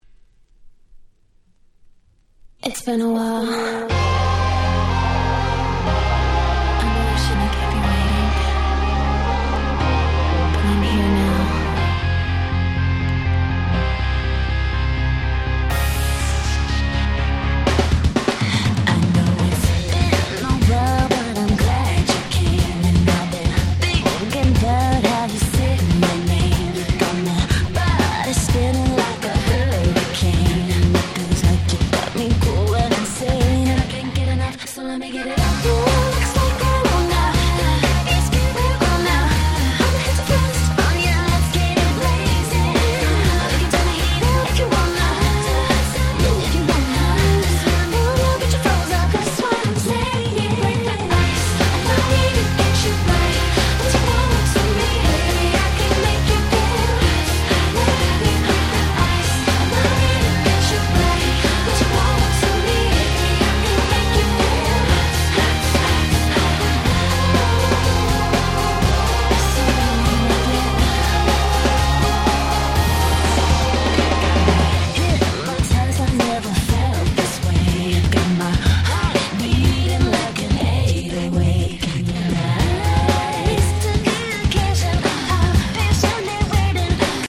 08' Smash Hit R&B / Vocal House / EDM / Pops !!